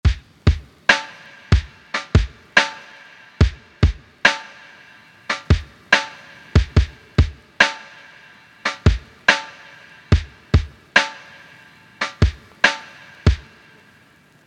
Kontinuierlicher Drum Beat
Bei Lo-Fi Hip Hop musst Du dich nicht mit komplizierten Rhythmen auseinandersetzen, denn was die Tracks so entspannt macht ist ein kontinuierlich durchlaufender Beat.
Hier kannst Du mit Kick und Snare einen Standard Hip Hop Beat angehen und dabei Kick und Snare nochmal hin und her schieben, für ein Laid-Back Gefühl.
kick_und_snare.mp3